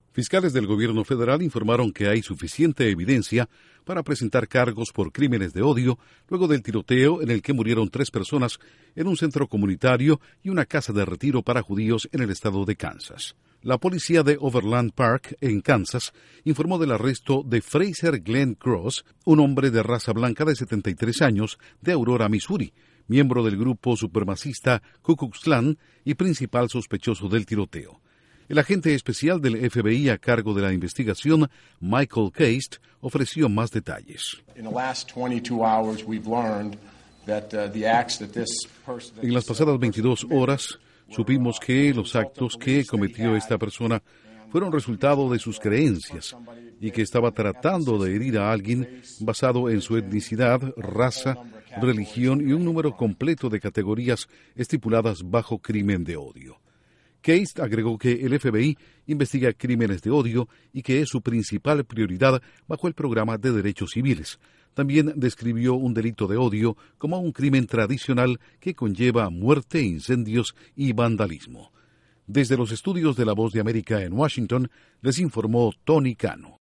desde la Voz de América en Washington